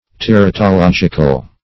Search Result for " teratological" : The Collaborative International Dictionary of English v.0.48: Teratological \Ter`a*to*log"ic*al\, a. (Biol.) Of or pertaining to teratology; as, teratological changes.
teratological.mp3